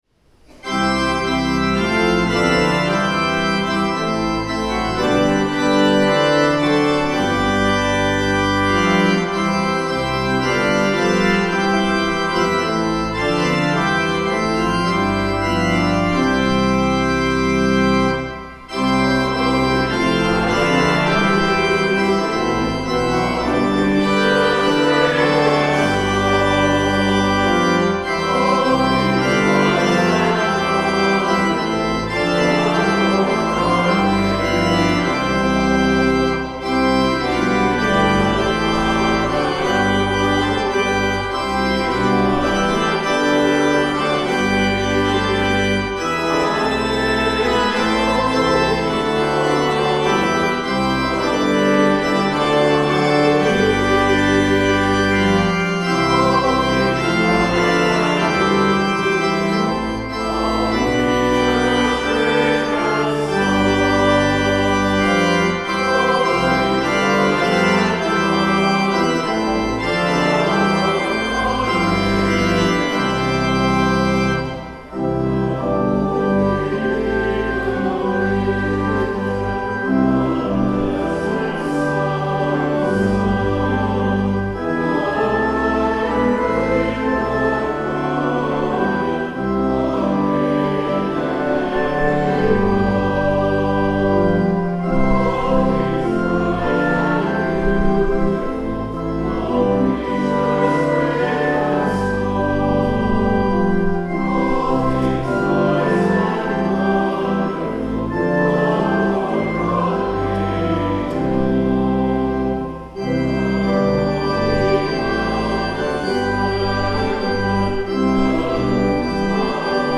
FOURTH SUNDAY AFTER PENTECOST Hymn: All Things Bright and Beautiful (Common Praise #415 – words below) Land Acknowledgement, Greeting, and Collect of the Day First Reading: Ezekiel 17:22-24 Psalm 92:1-4, 11-14 / Lord, it is good to give thanks to you Second Reading: 2 Corinthians...